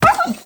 Minecraft Version Minecraft Version 1.21.5 Latest Release | Latest Snapshot 1.21.5 / assets / minecraft / sounds / mob / wolf / puglin / hurt1.ogg Compare With Compare With Latest Release | Latest Snapshot